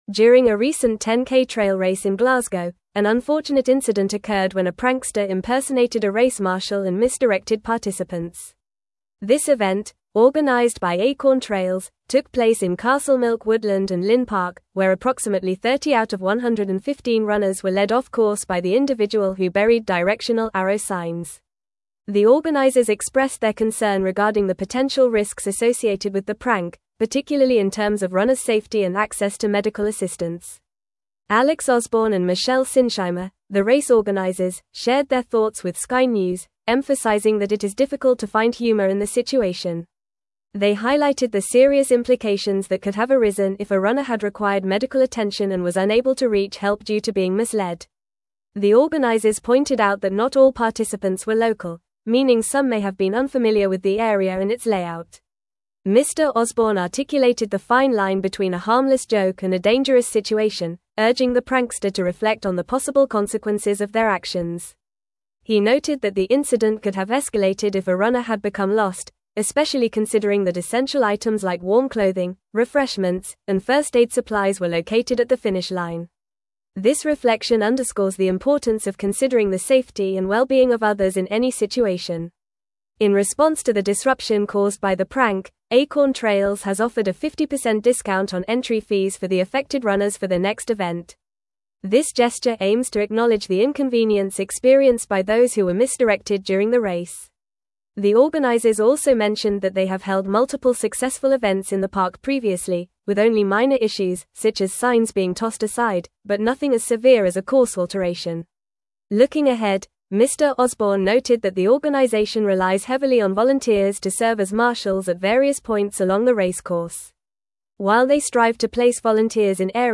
Fast
English-Newsroom-Advanced-FAST-Reading-Prankster-Disrupts-Glasgow-10k-Trail-Race-Direction.mp3